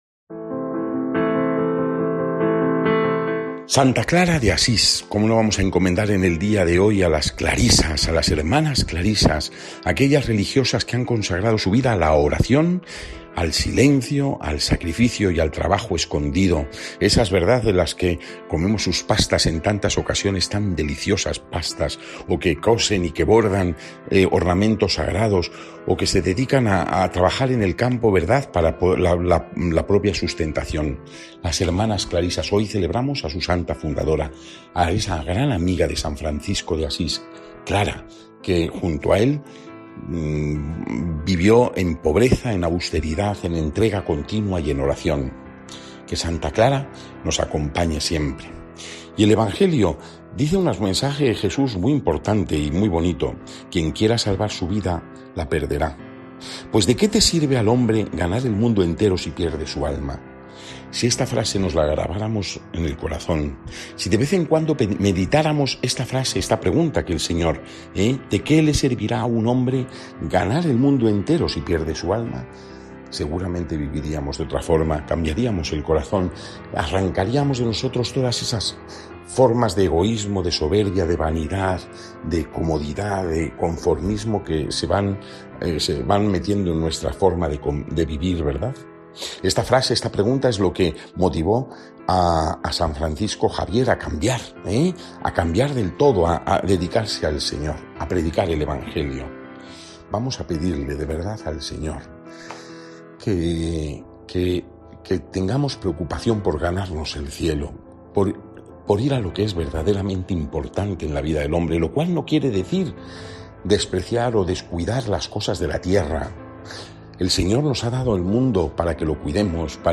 Evangelio según san Mateo (16,24-28) y comentario